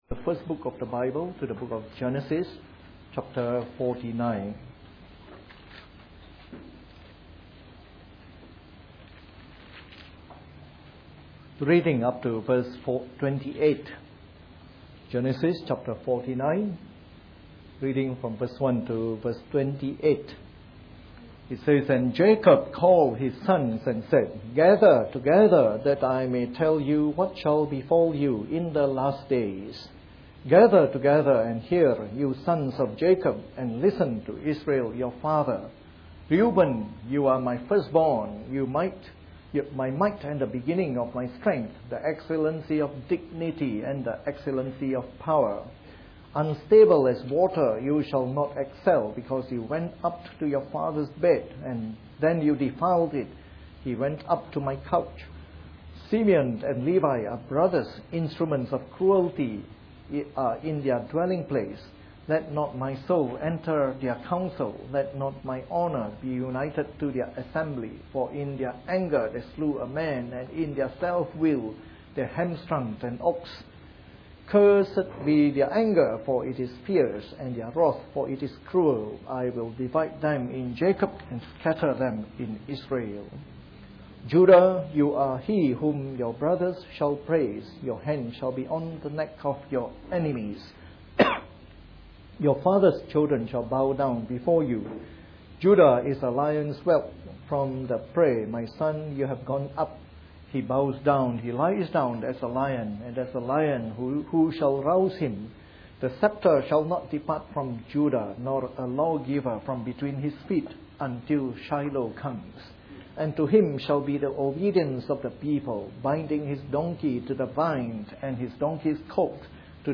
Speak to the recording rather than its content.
Part of our series on the Book of Genesis delivered in the Morning Service.